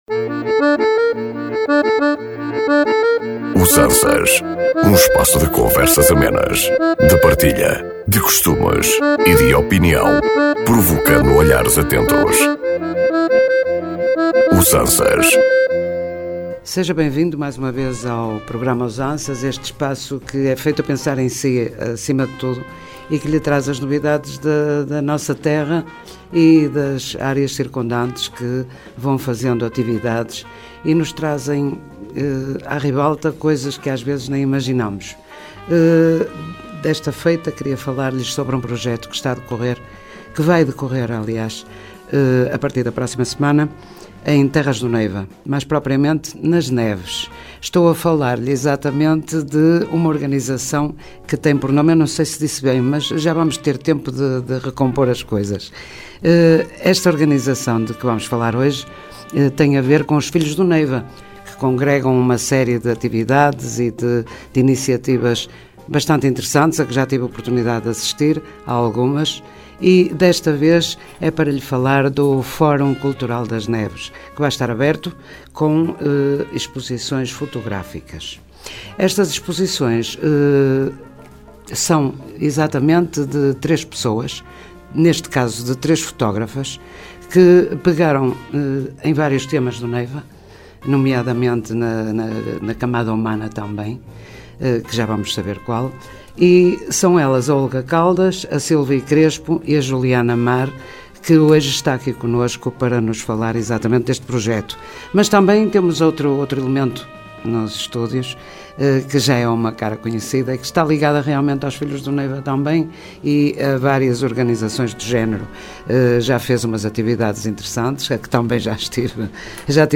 Um espaço de conversas amenas, de partilha, de costumes e de opinião, provocando olhares atentos.